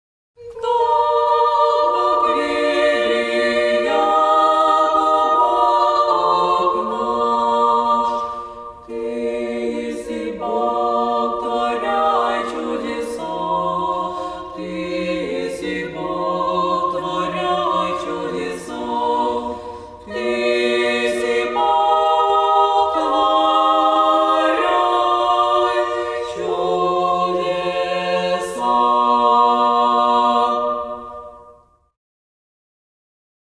Православни песнопения